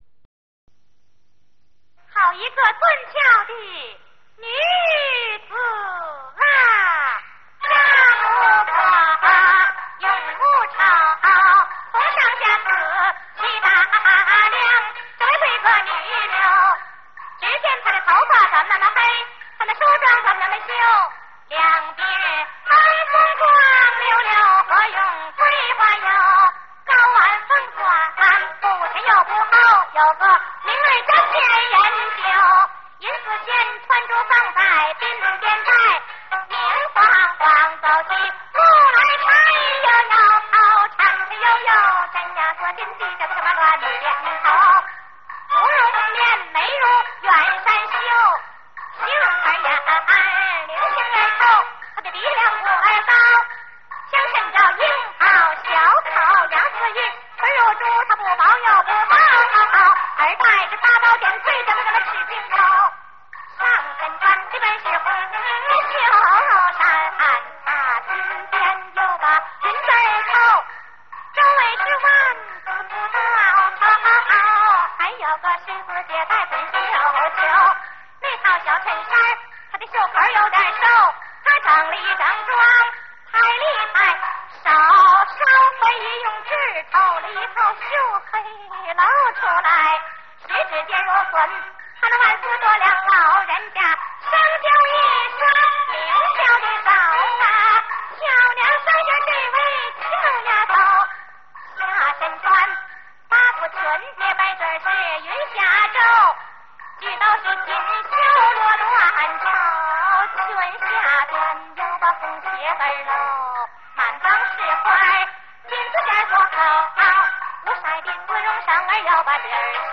DVD原抓 历史录音 单声道 音质有限